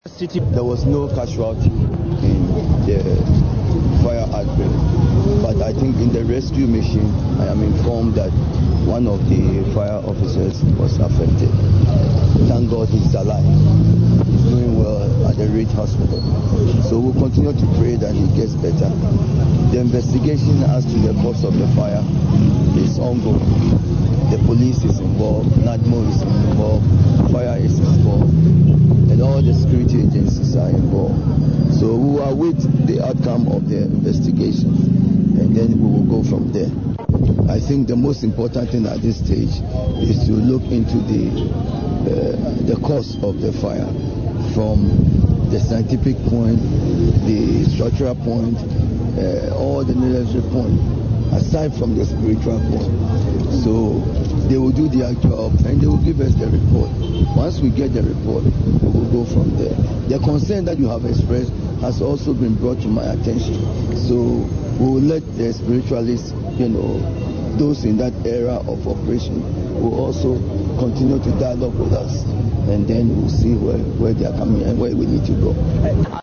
Speaking to Citi FM, Friday morning after touring the accident scene, Dr Vanderpuije noted that, “I think the most important thing at this stage is to look at the cause of the fire from the scientific point, the structural point, all the necessary points, aside from the spiritual point; so they will do their job and they will give us the report. Once we get the report, we will go from there…we will let the spiritualists, those in that area of operation to continue to dialogue with us to see what they need to do.”
Click to listen to Dr Vandepuije